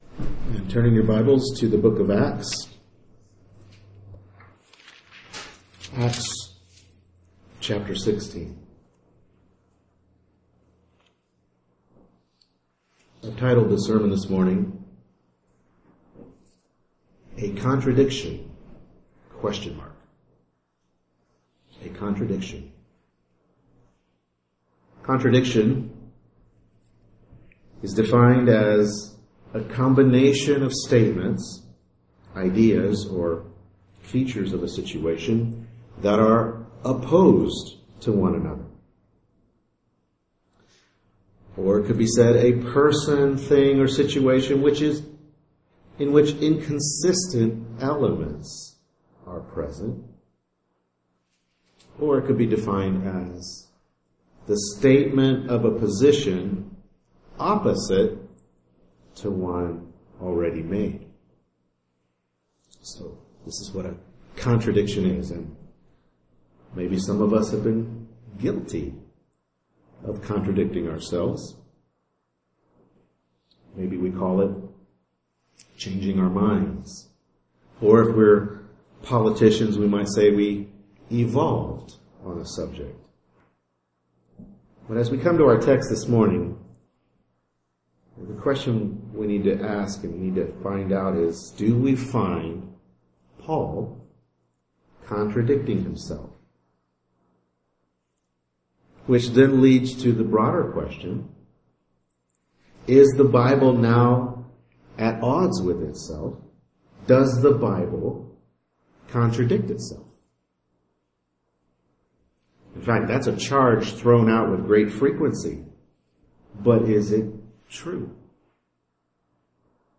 Acts 16:1-5 Service Type: Morning Worship Service Bible Text